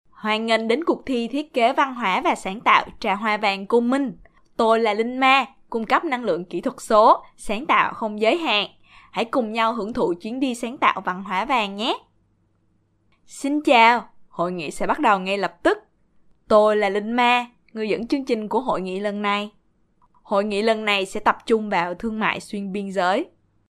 当前位置：首页> 样音试听 >优选合集 >外语配音合集 >越南语配音